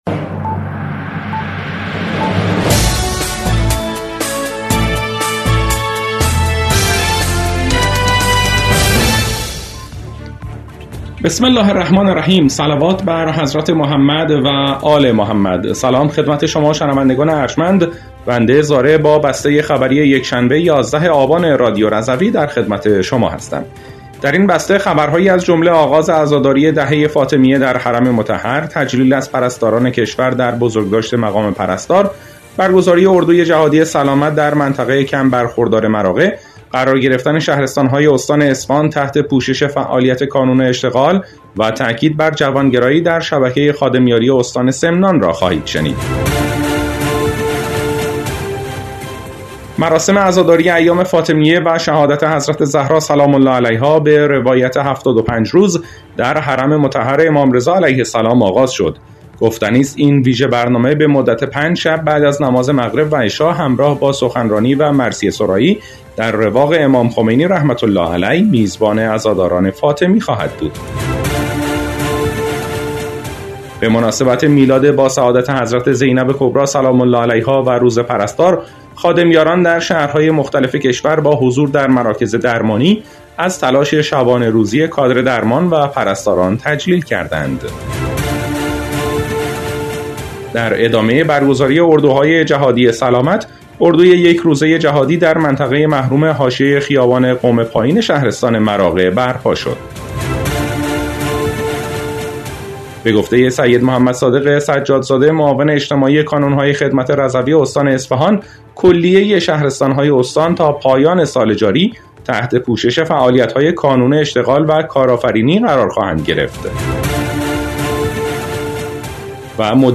بسته خبری ۱۱ آبان ۱۴۰۴ رادیو رضوی؛